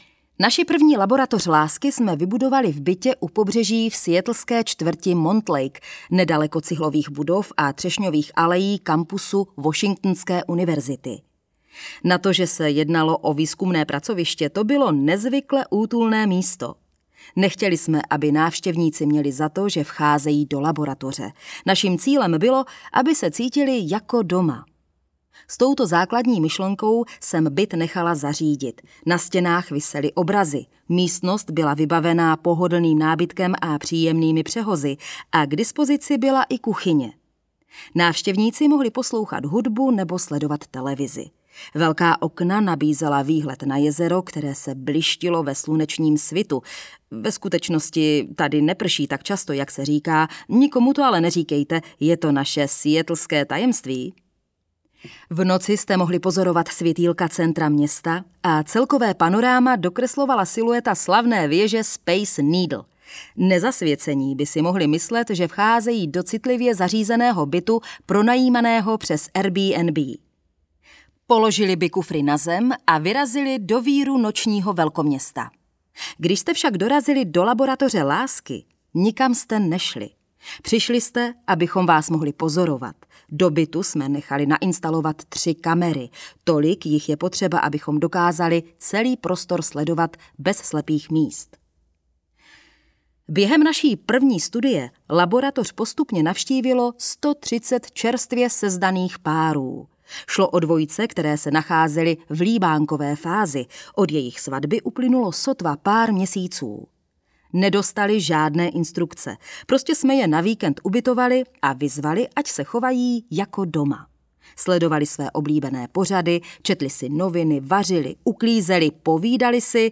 Audiokniha Vitamín L - Julie Schwartz Gottman, John Gottman | ProgresGuru
audiokniha